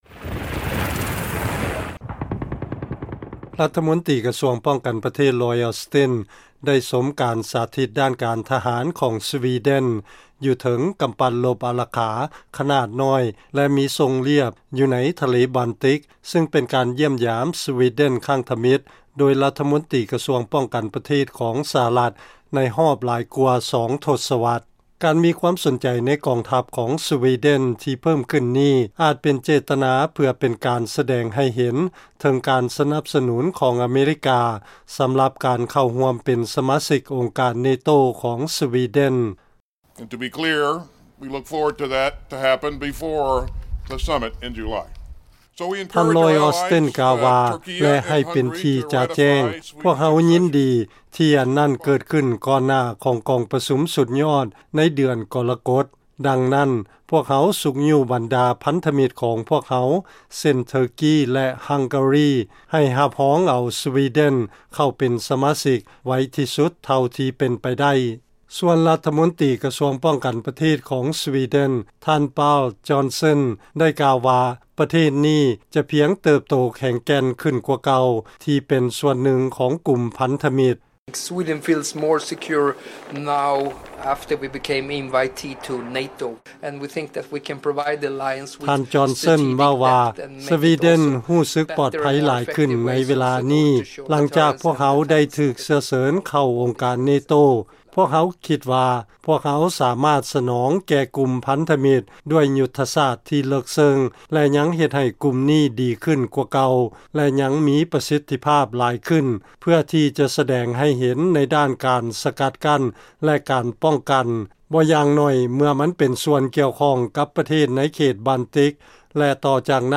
ຟັງລາຍງານ ລມຕ ລອຍ ອອສຕິນ ຕ້ອງການໃຫ້ເທີກີ ຮັງກາຣີ ຮັບຮອງເອົາ ສະວີເດັນ ທີ່ພະຍາຍາມຂໍເຂົ້າເປັນສະມາຊິກ ອົງການ NATO ກ່ອນເດືອນກໍລະກົດ